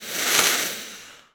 snake_hiss_04.wav